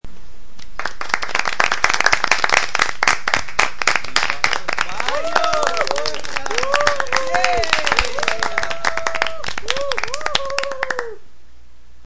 Personas aplaudiendo y gritando: Efectos de sonido
Este efecto de sonido ha sido grabado para capturar la energía y la espontaneidad de una multitud aplaudiendo y vitoreando, proporcionando un sonido auténtico y vibrante que se integrará perfectamente en tus proyectos.
Tipo: sound_effect
Personas aplaudiendo y gritando.mp3